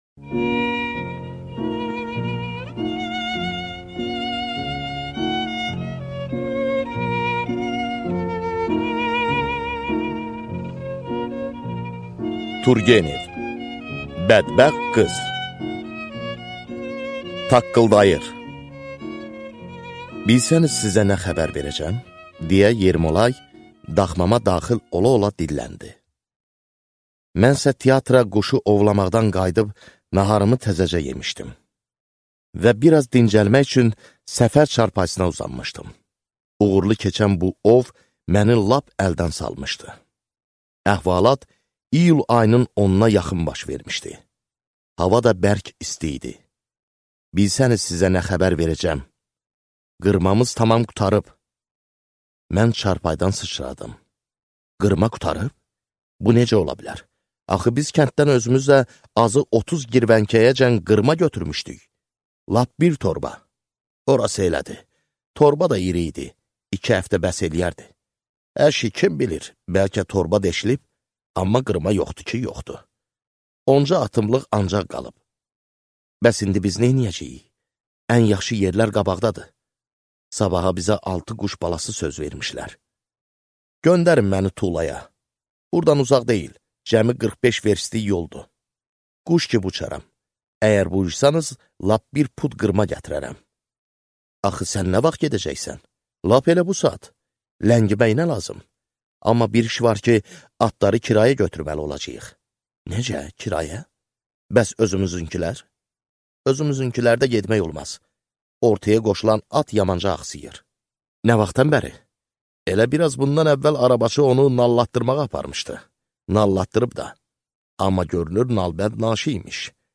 Аудиокнига Bədbəxt qız | Библиотека аудиокниг